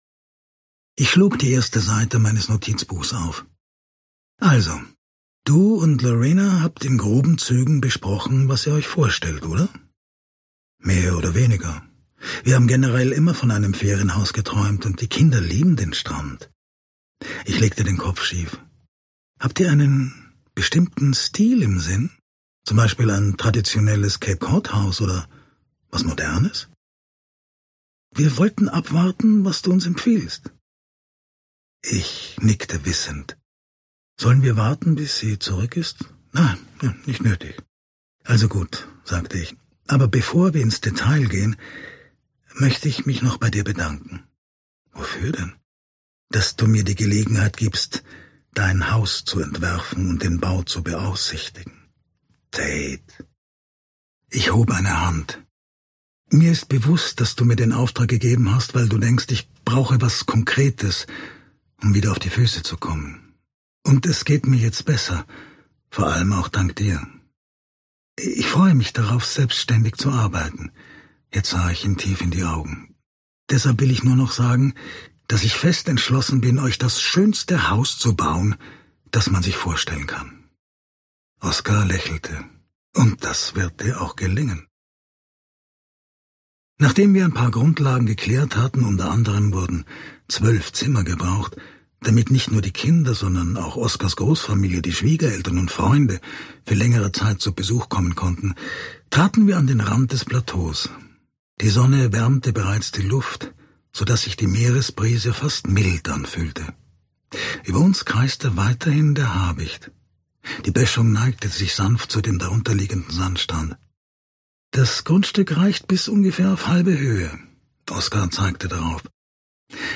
Alexander Wussow (Sprecher)
leicht gekürzte Lesung